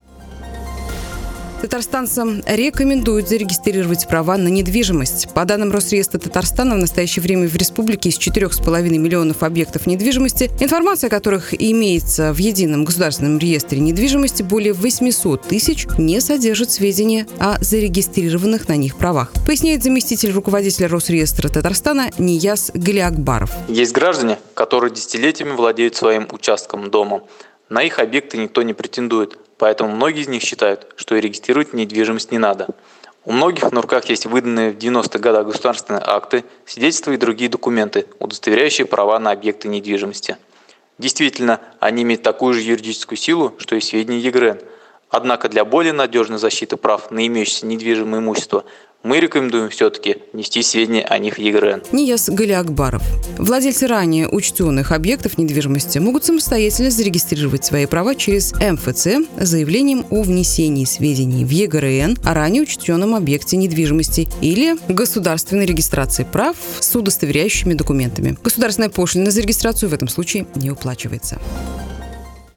Аудиорепортаж: